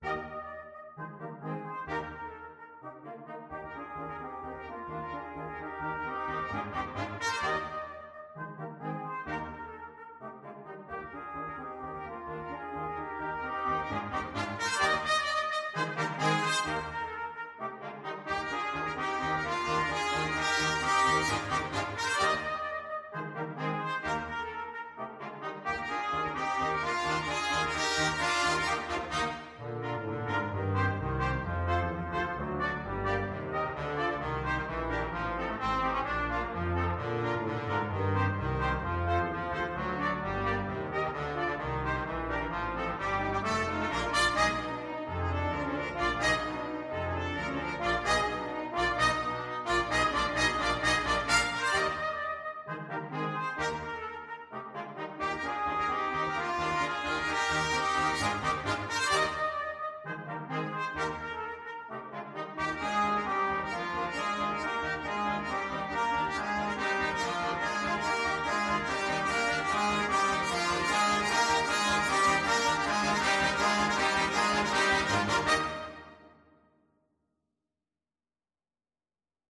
Brass Quintet version
Trumpet 1Trumpet 2French HornTromboneTuba
2/4 (View more 2/4 Music)
Classical (View more Classical Brass Quintet Music)